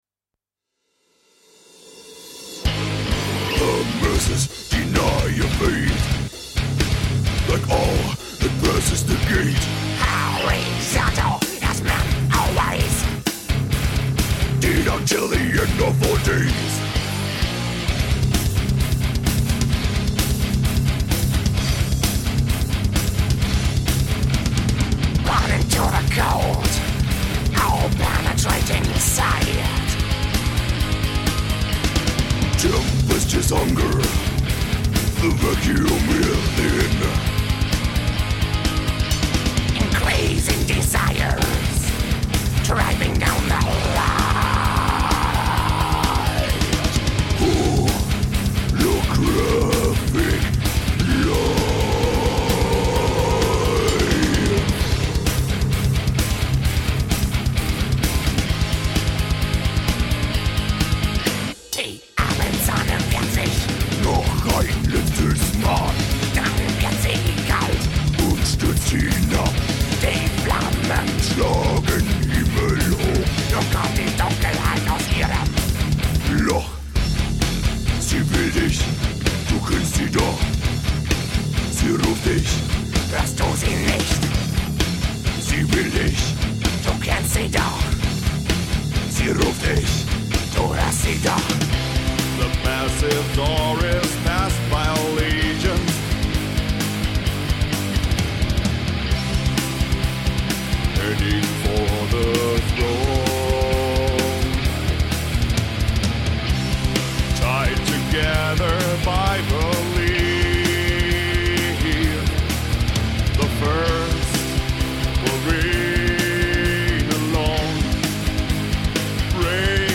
in Bad Kreuznach gegründete Metalband.
Demo Songs